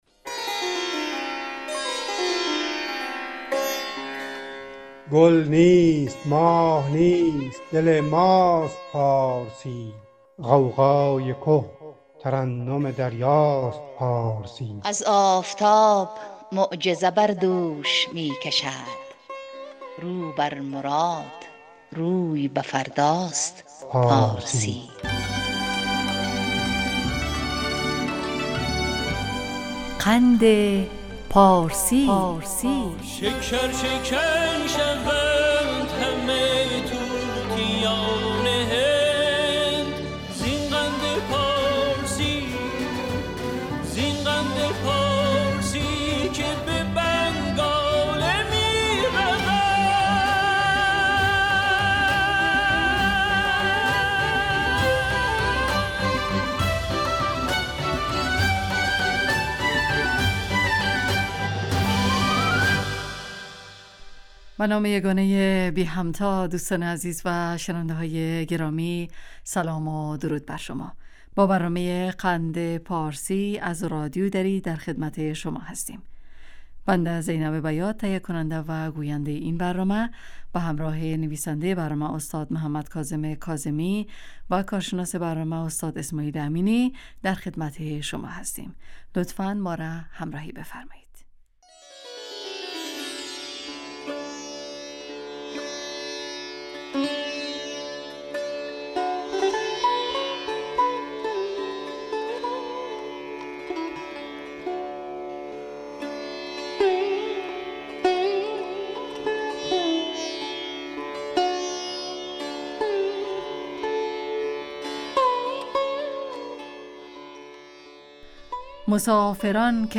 برنامه ادبی رادیو دری